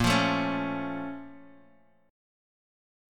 BbmM7 chord